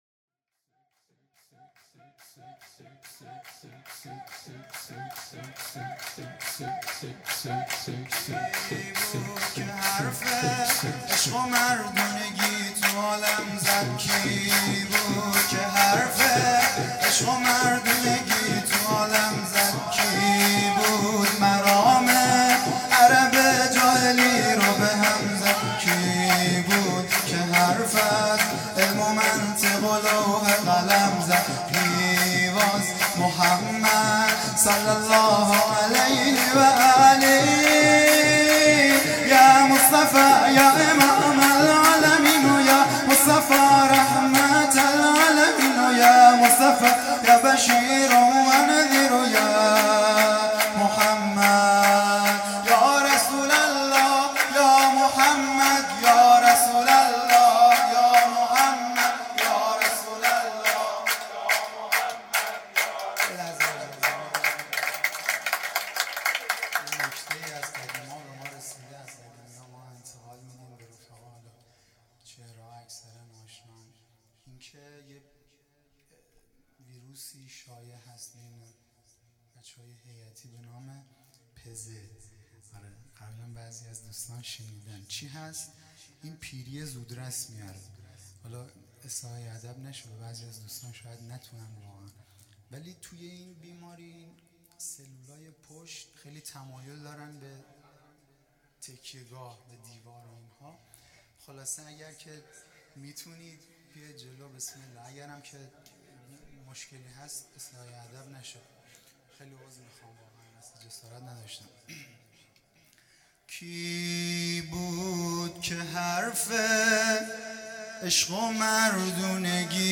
سرود
مبعث رسول اکرم(ص)